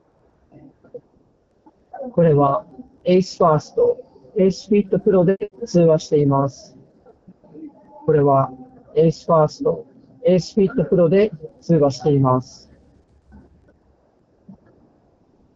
通話時のノイズキャンセリング
スピーカーから雑踏音をそこそこ大きなボリュームで流しながらマイクで収録した音声がこちら。
完全に雑音を除去しているわけではないですが、これくらいなら通話相手が聞き取りにくいことはなさそうです。
マイクも若干ボワっとしているものの悪くはないので、仕事でのちょっとした打ち合わせ程度であれば使えると思います。